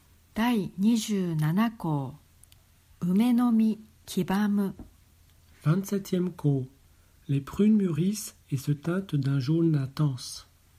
Les 72 saisons — lecture en japonais et français